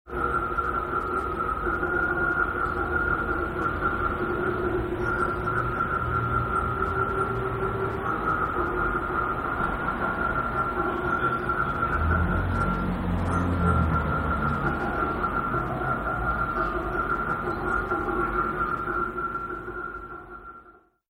It consisted of two circuits of microphone, loudspeaker and noise gate.  The microphones pick up the ambient sound of the space, acoustically focussed by the curved surfaces at each side of the entrance area and build it up to the point of feedback.  This sound is cut off by the noise gate when a certain volume is reached allowing the listener to become part of the process.
This double system is designed so that the electronic part of the chain is independent but the acoustic part of the chain is interdependent - the two systems hear and react to each other.
A documentary fragment of a moment within the installation, recorded on 13 February 2003